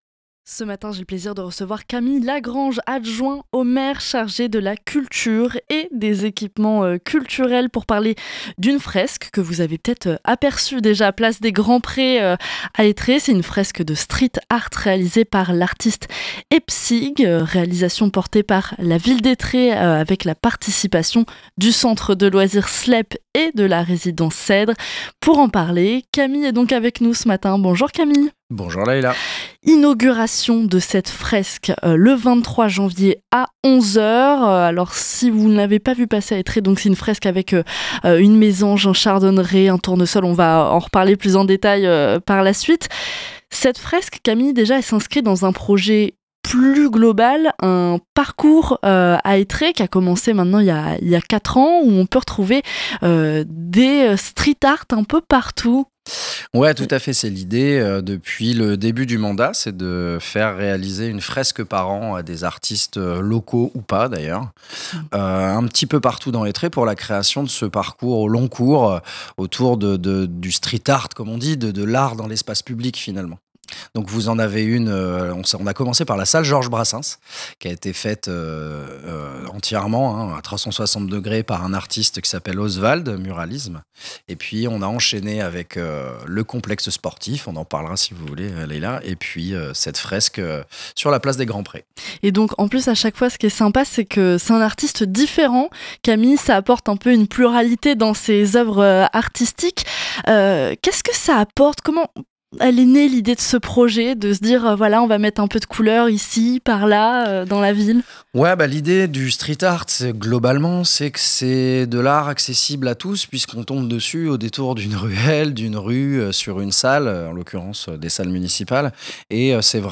Camille LAGRANGE, adjoint au maire chargé de la culture et des équipements culturels était avec nous pour en parler.